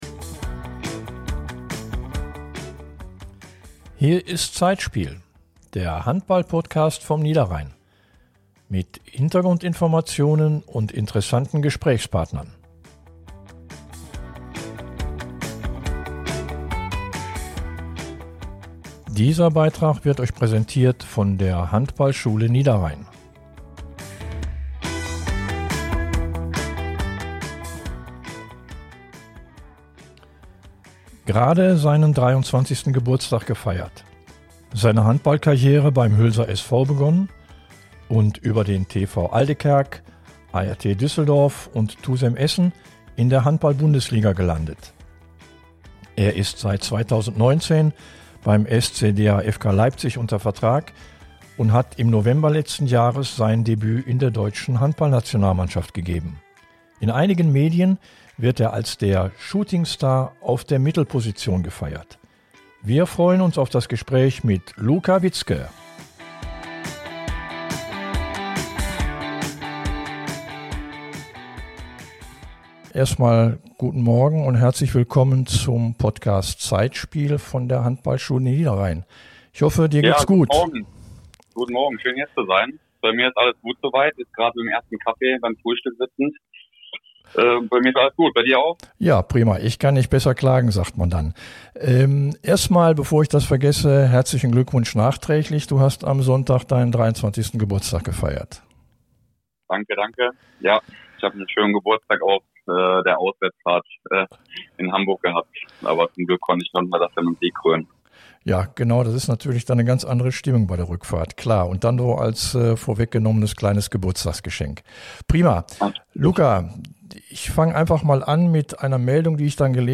ZEITSPIEL im Gespräch mit Luca Witzke ~ Zeitspiel Podcast